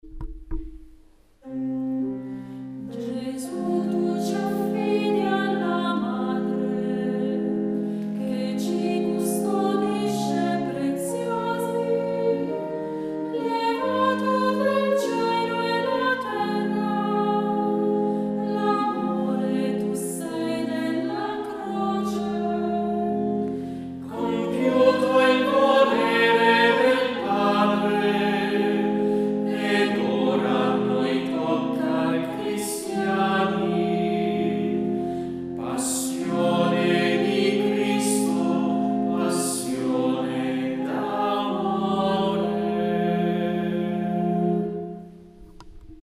I cinque interventi cantati dal coro, vanno inseriti nei seguenti punti: